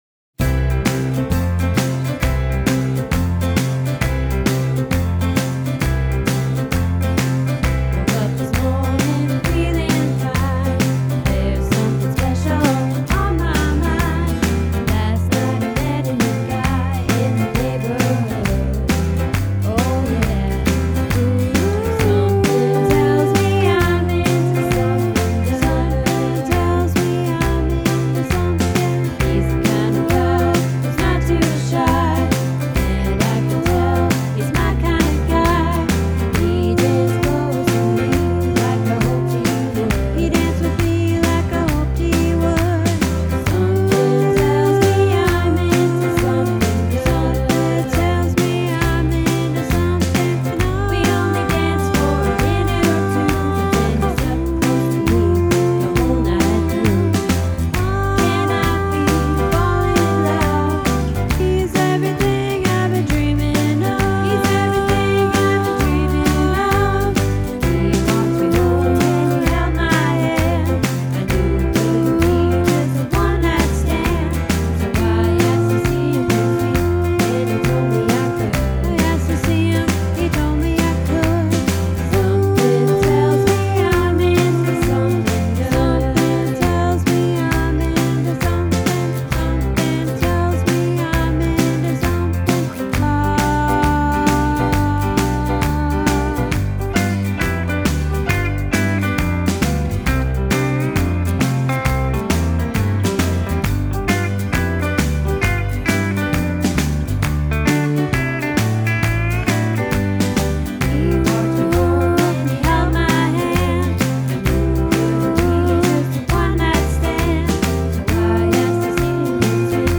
Into Something Good - Tenor